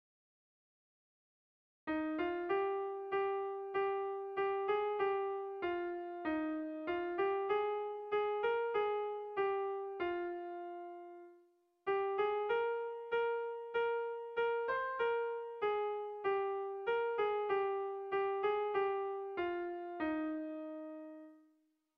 Melodías de bertsos - Ver ficha   Más información sobre esta sección
Erlijiozkoa
Lauko handia (hg) / Bi puntuko handia (ip)
AB